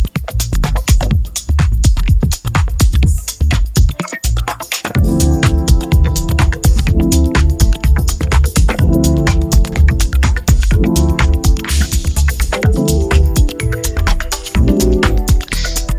audio-to-audio music-generation